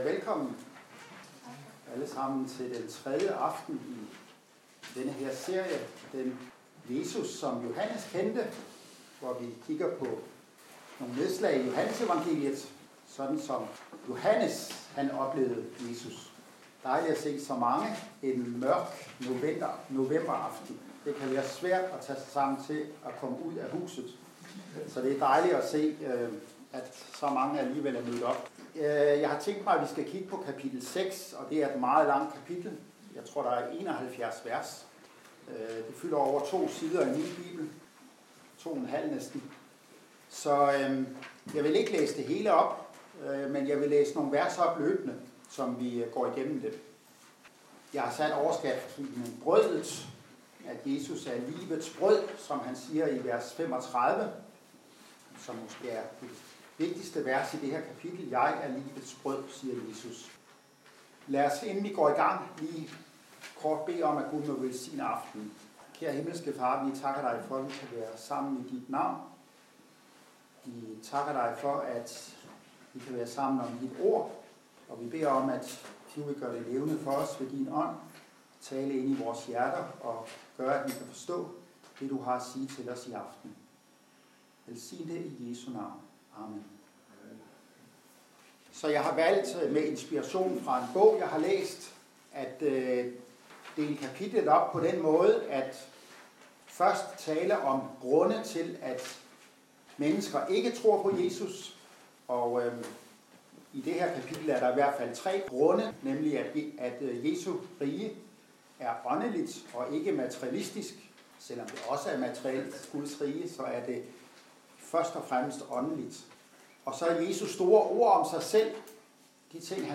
Bibelundervisning